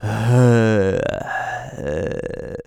Male_High_Roar_04.wav